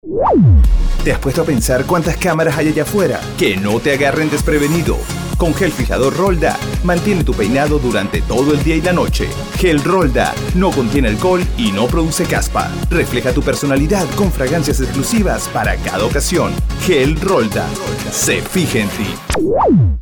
Poised, confident, with strong speaking voice as well as talent for on-air interviewing. With a uniquely refreshing and crisp tone of voice has lended his craft to international brands like: CosmĂ©ticos Rolda MBE Etc BaterĂas MAC Tanagua Panna TaxCare Orlando